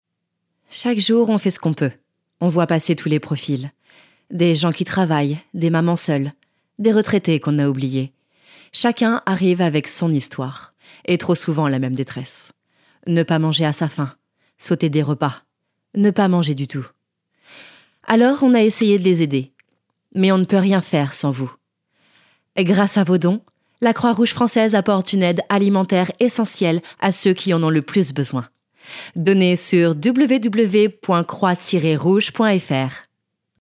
Voix off
25 - 45 ans - Mezzo-soprano